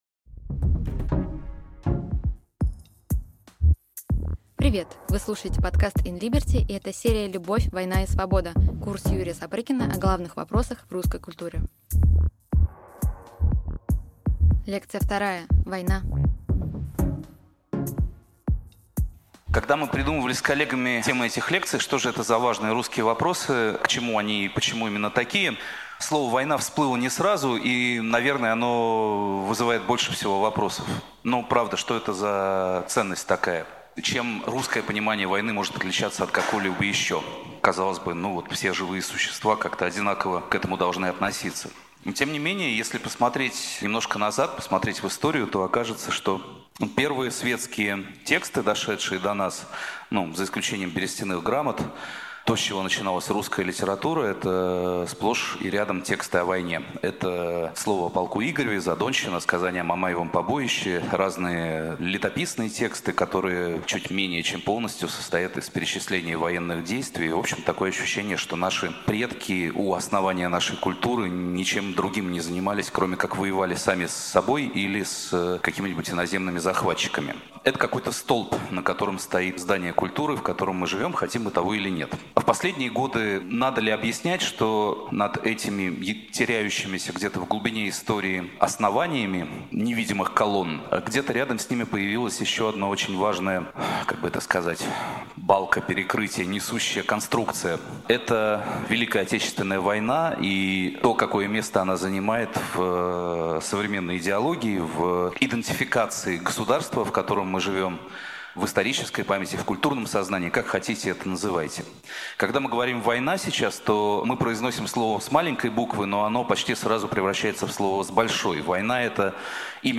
Аудиокнига Лекция 2.
Война Автор Юрий Сапрыкин Читает аудиокнигу Юрий Сапрыкин.